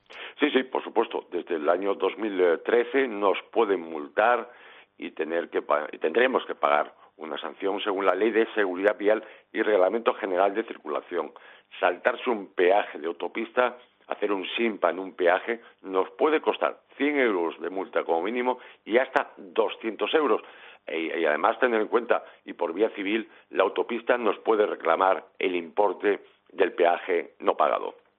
contesta a un oyente en Poniendo las Calles que cuestiona sobre un aspecto que viene indicado en la Ley de Seguridad Vial y Reglamentos General de Circulación